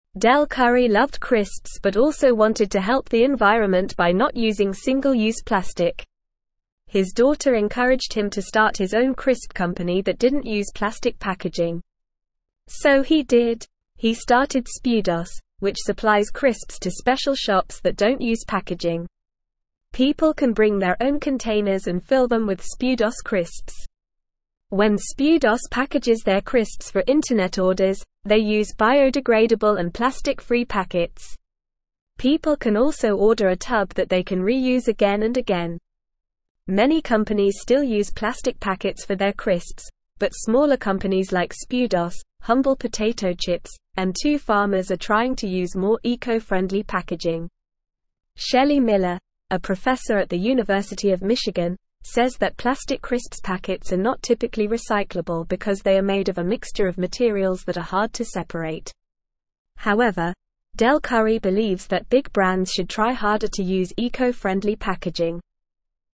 English-Newsroom-Beginner-NORMAL-Reading-No-Plastic-Crisp-Packets-for-Spudos.mp3